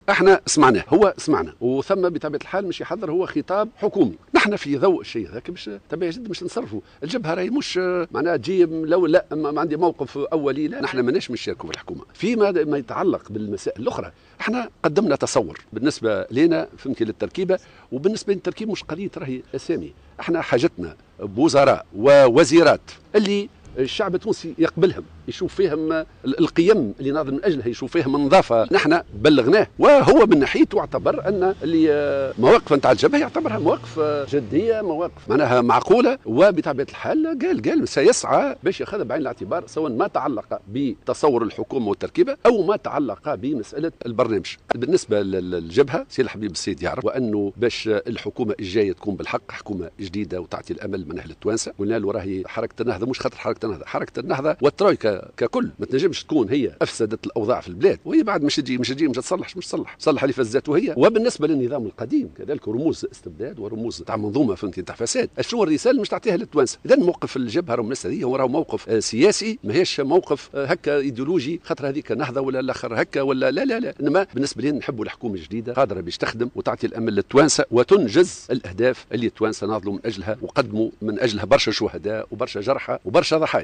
Le porte-parole du Front Populaire, Hamma Hammami, a formellement assuré jeudi soir au micro de Jawhara Fm que la coalition de gauche maintient sa position par rapport au gouvernement d’Habib Essid.